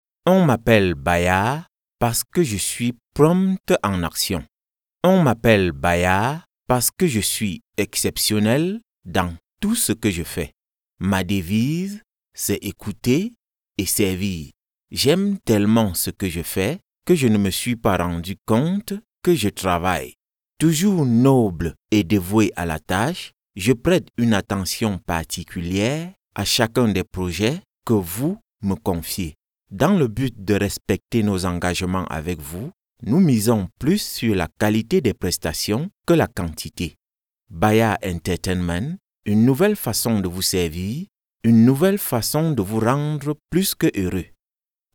French voice over, African French, Skype and Zoom, French Narration, French E-learning, French Commercial, French Documentary.
Sprechprobe: Werbung (Muttersprache):
I have a home studio to do client's work quick as possible.
Commercial - Promoton.mp3